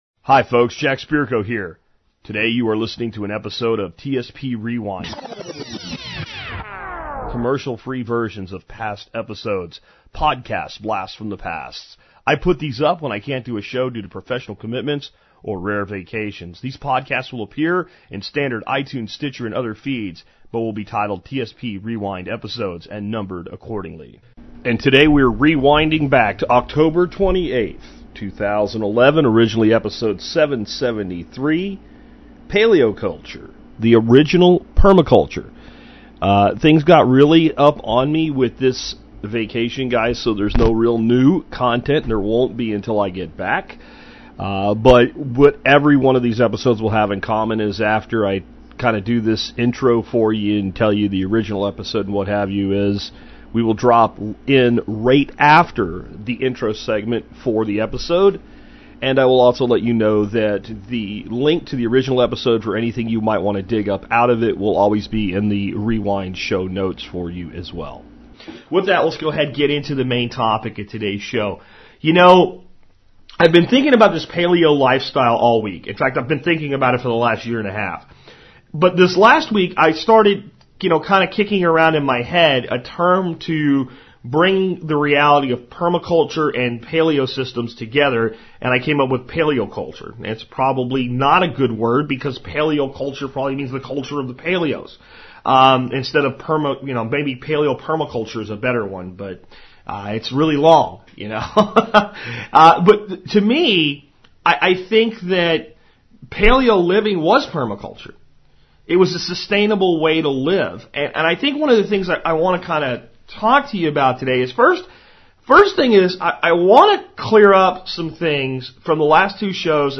Tune in to actually hear ChatGPT respond to my questions about using it … Continue reading →